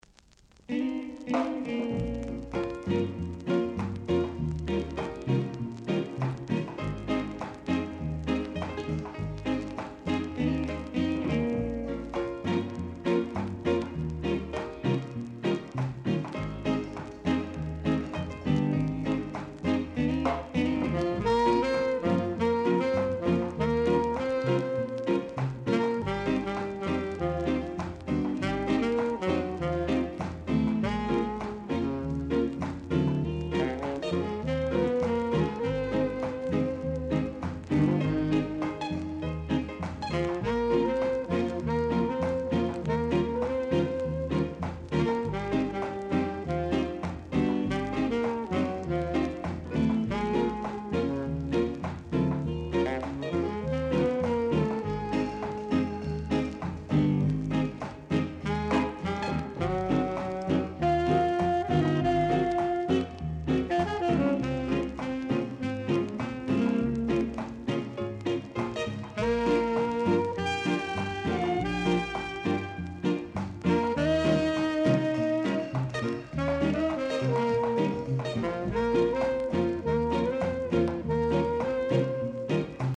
R.Steady Inst
Rare! great rock steady inst & vocal!